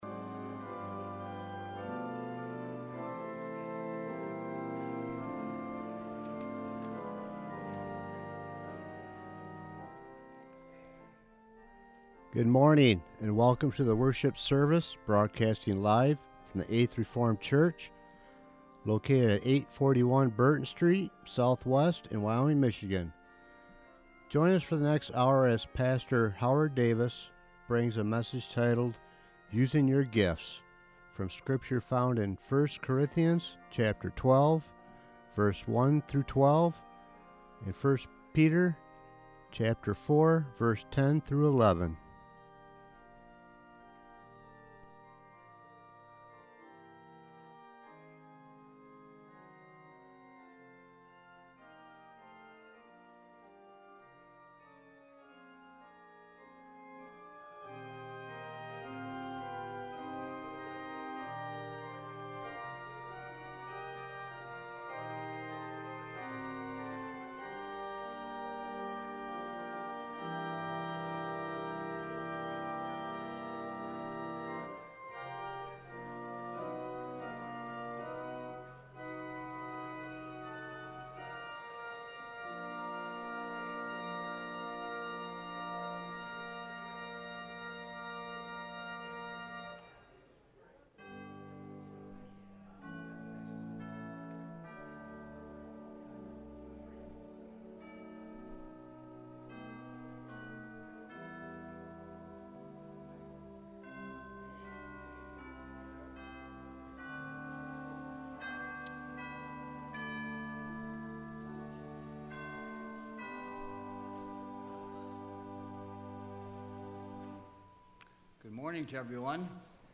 Worship Services | Eighth Reformed Church